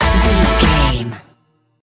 Ionian/Major
D
Fast
drums
electric guitar
bass guitar